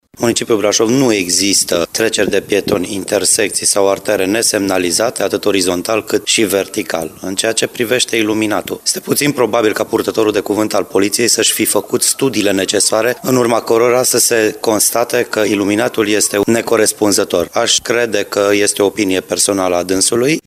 Managerul public al municipiului Brașov, Miklos Gantz: